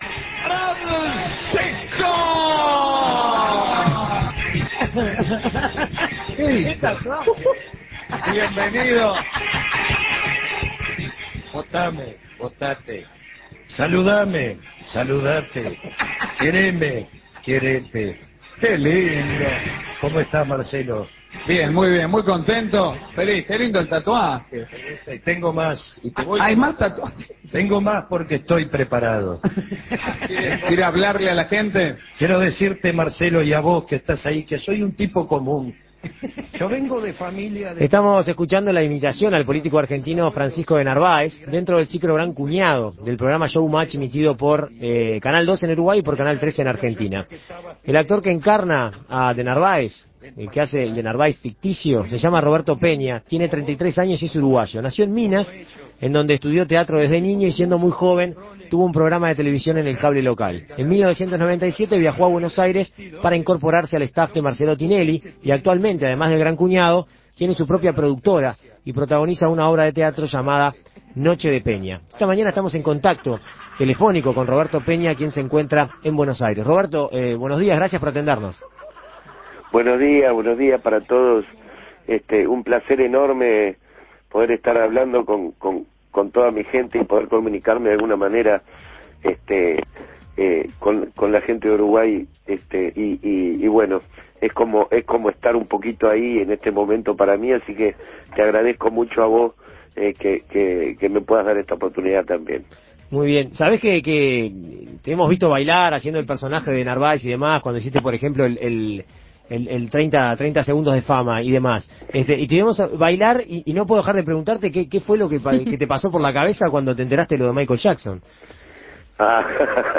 En Perspectiva Segunda Mañana dialogó con el artista.